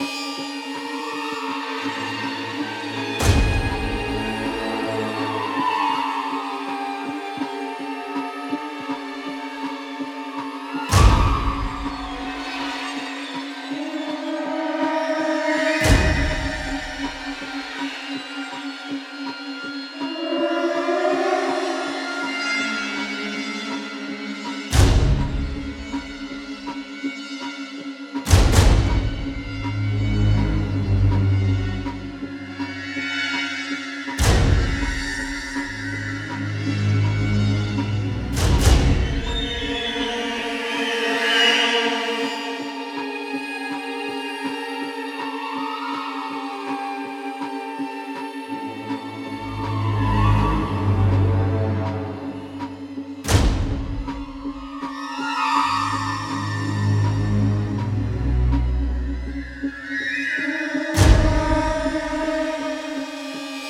06 - Suspense